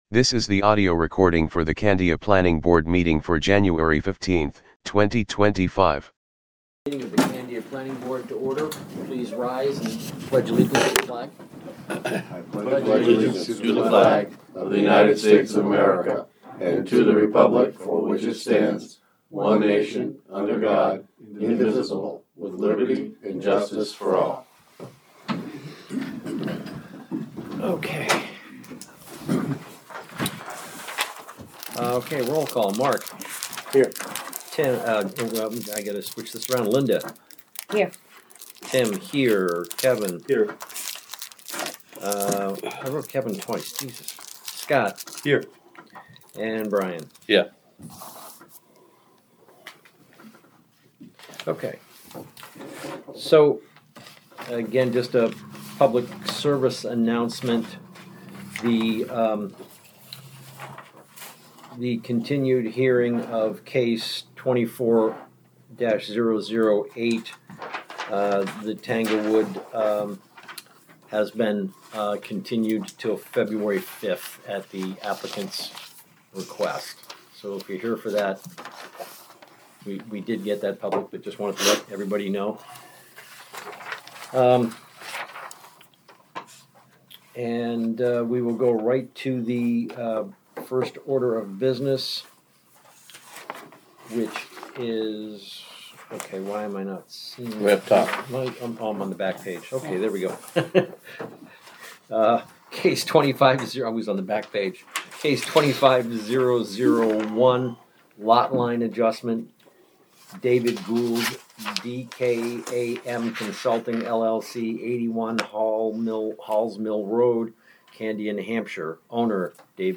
Audio recordings of committee and board meetings.
Planning Board Meeting